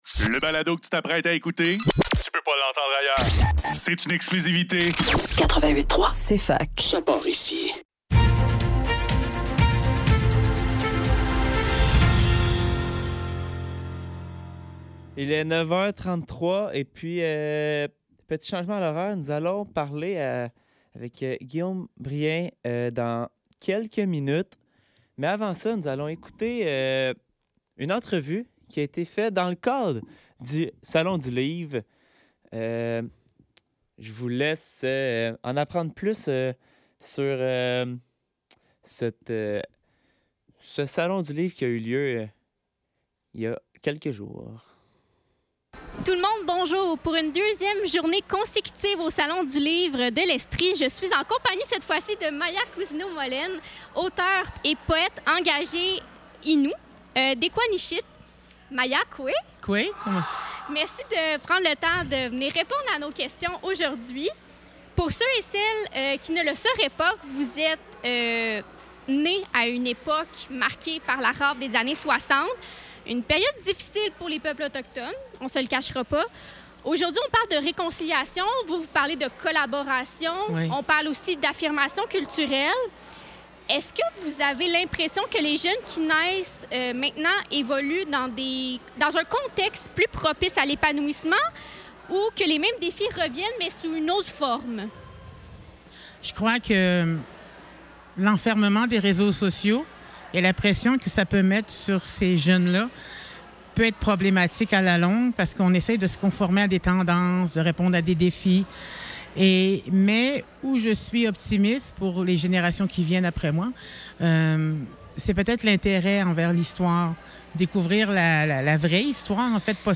Le neuf - Salon du livre de l'Estrie: entrevue